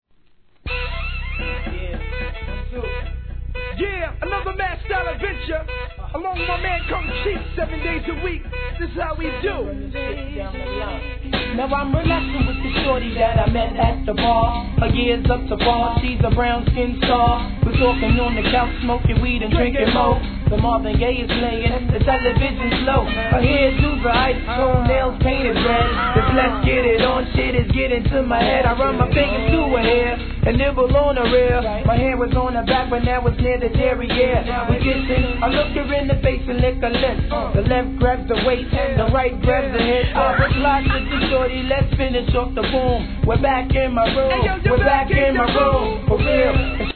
HIP HOP/R&B
1993年、N.Y.インディーNEW SCHOOL!!